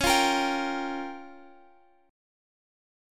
C#6b5 chord